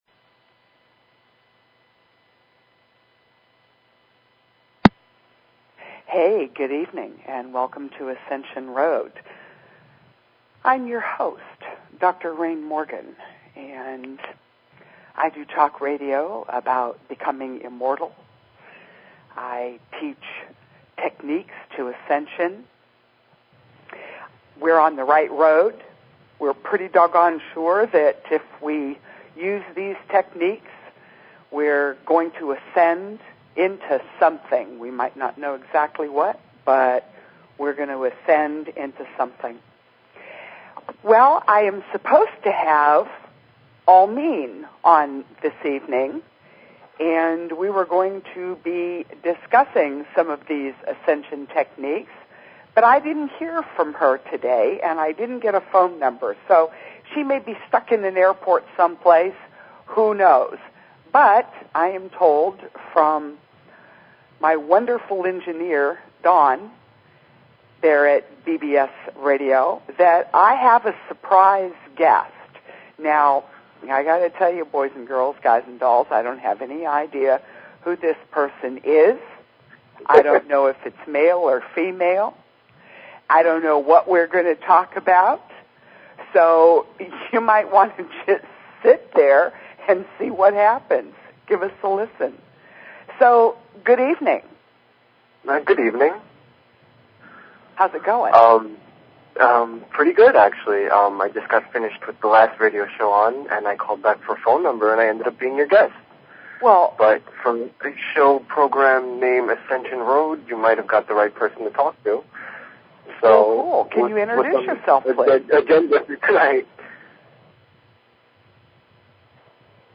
Talk Show Episode, Audio Podcast, Ascension_Road and Courtesy of BBS Radio on , show guests , about , categorized as